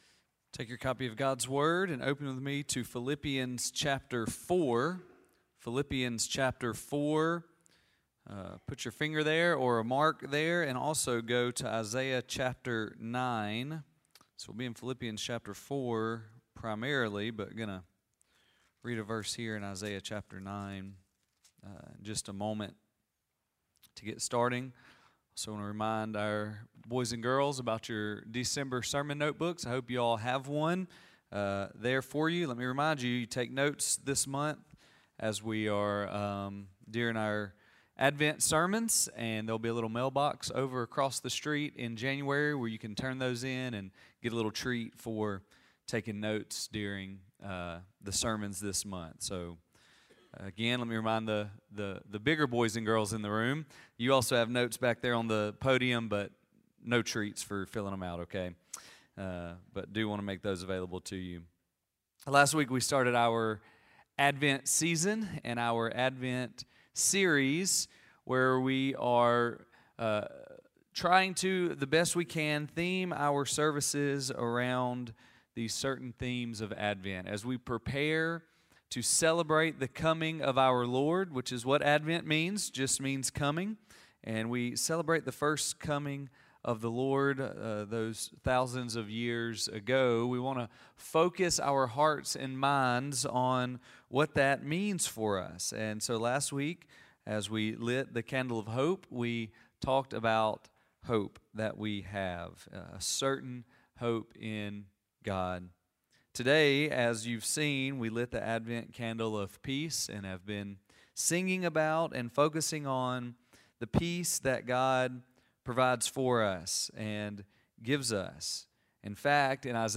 Sermon Library